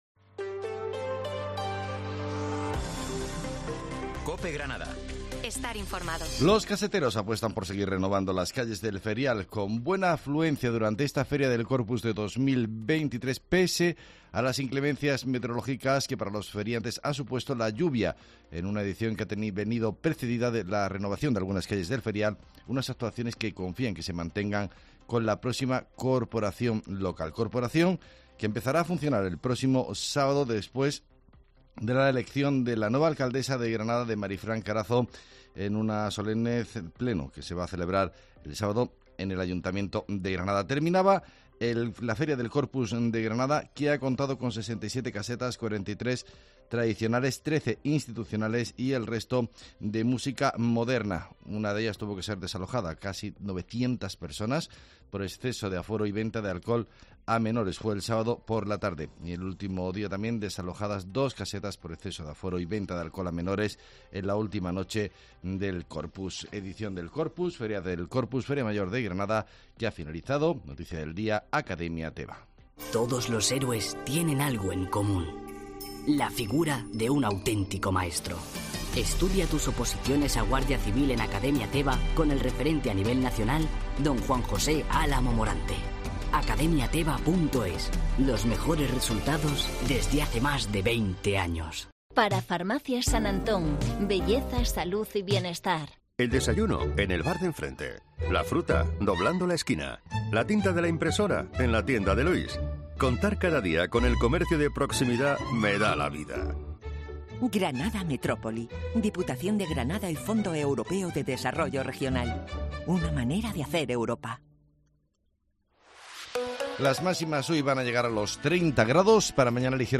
Herrera en Cope Granada, Informativo del 12 de junio